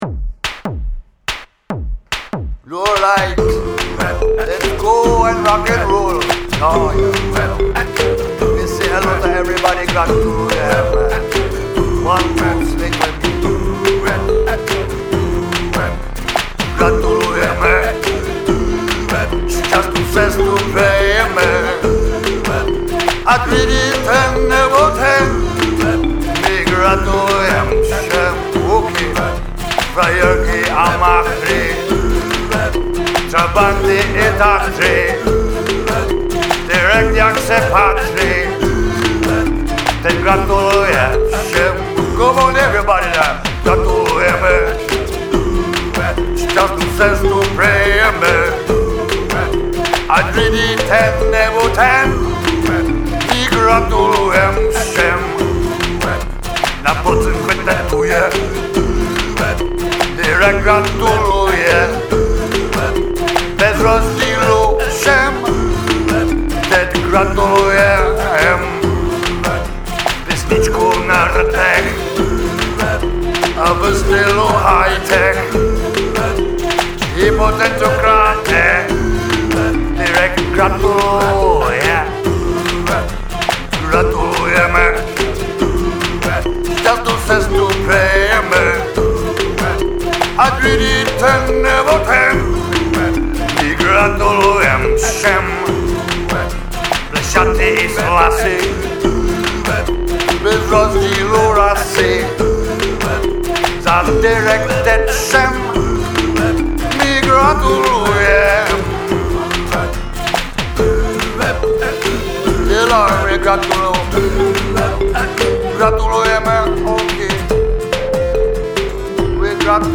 electro-jamaican song